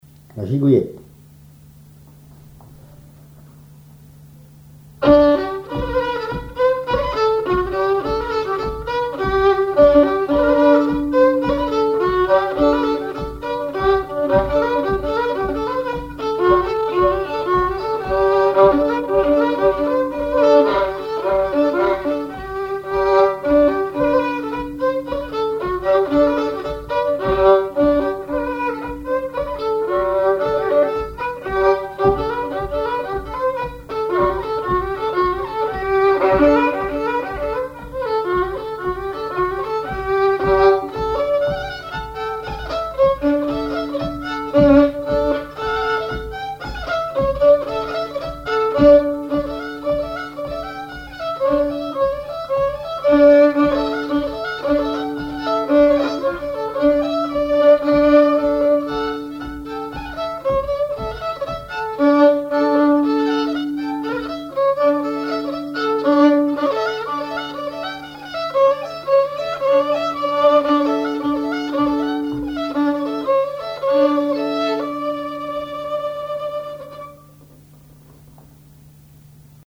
violoneux, violon
danse : gigouillette
instrumentaux au violon mélange de traditionnel et de variété
Pièce musicale inédite